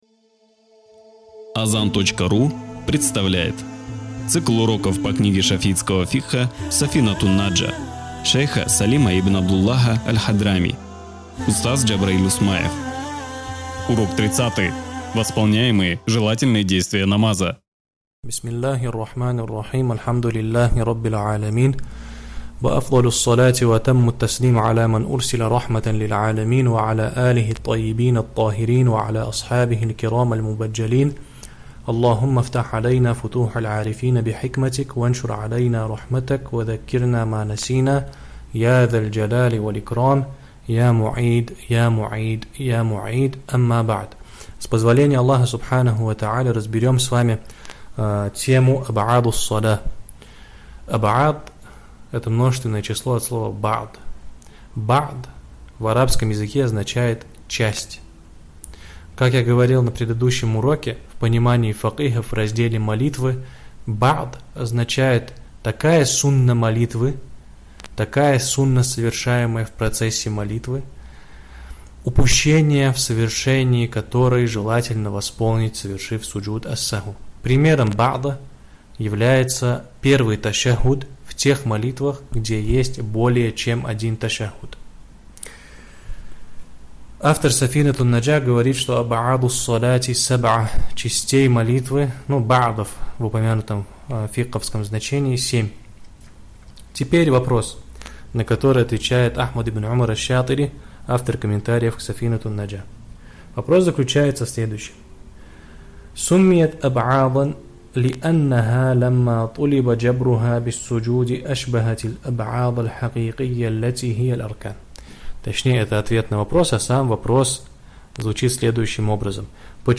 ЦИКЛЫ УРОКОВ